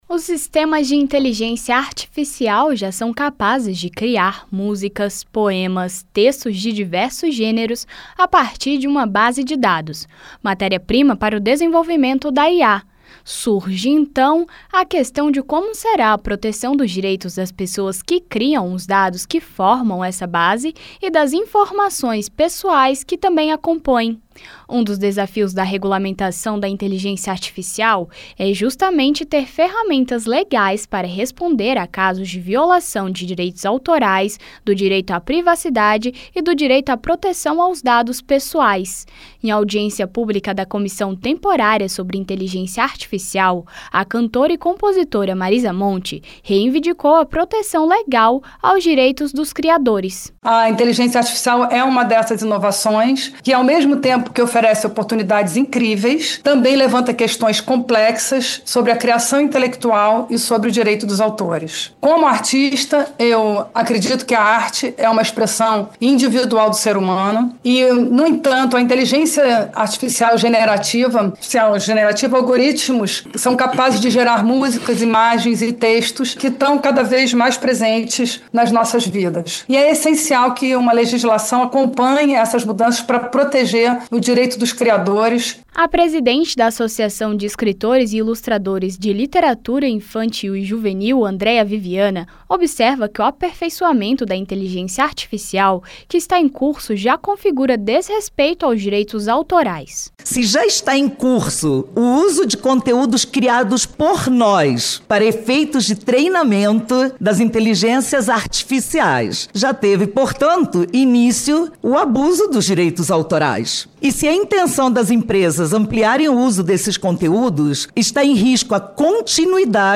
Em audiência pública da Comissão Temporária sobre Inteligência Artificial na terça-feira (3), a cantora e compositora Marisa Monte reivindicou a proteção legal aos direitos dos criadores.